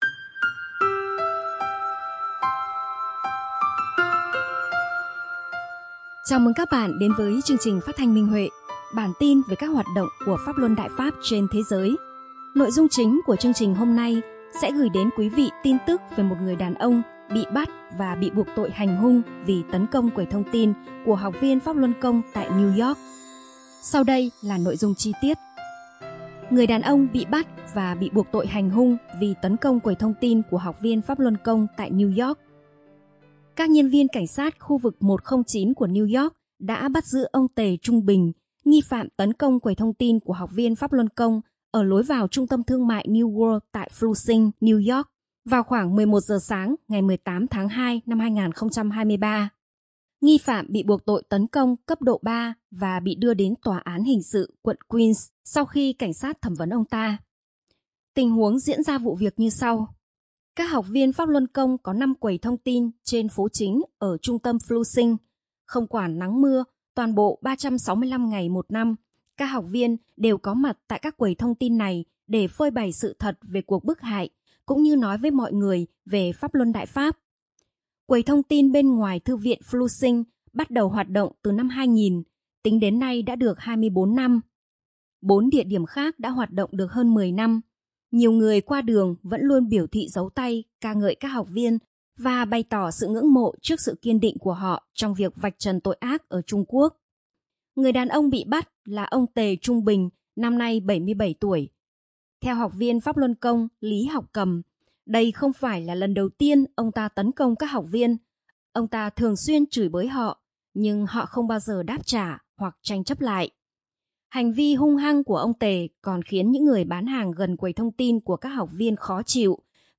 Chào mừng các bạn đến với chương trình Phát thanh Minh Huệ – bản tin về các hoạt động của Pháp Luân Đại Pháp trên thế giới. Nội dung chính của chương trình hôm nay sẽ gửi đến quý vị tin tức về một người đàn ông bị bắt và bị buộc tội hành hung vì tấn công quầy thông tin của học viên Pháp Luân Công tại New York.